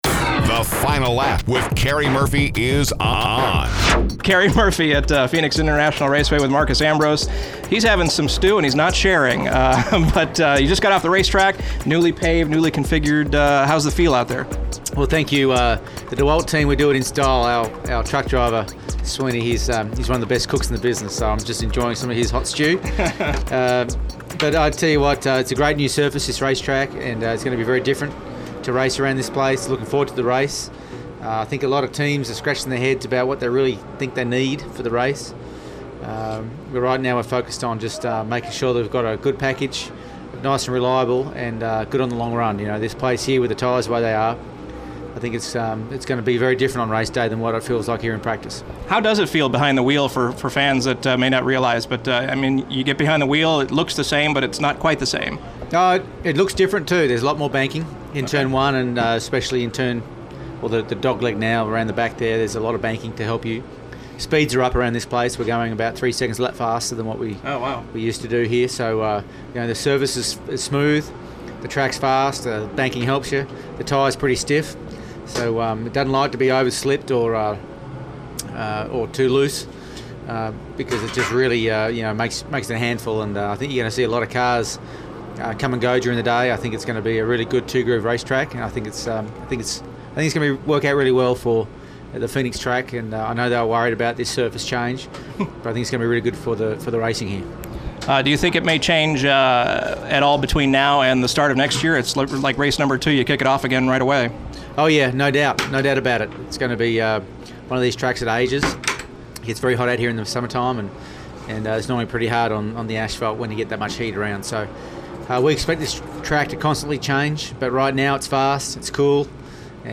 Audio Interview: Marcos Ambrose